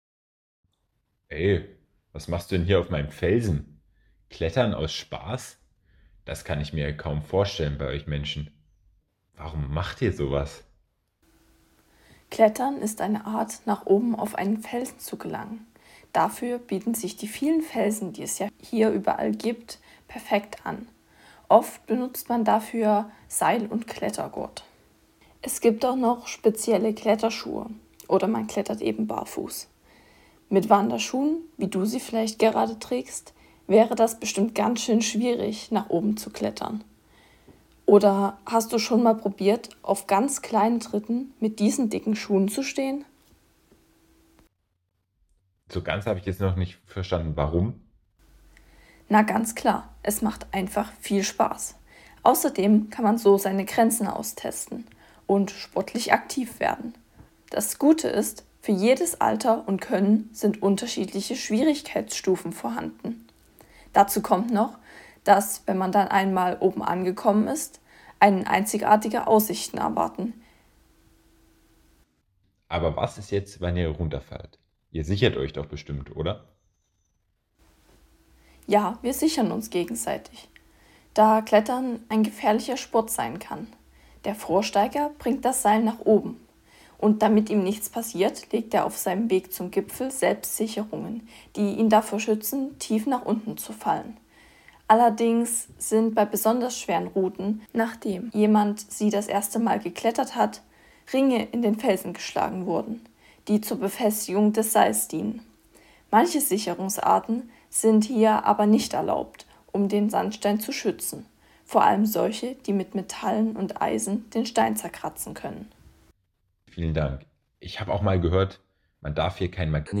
Billy trifft einen Kletterer.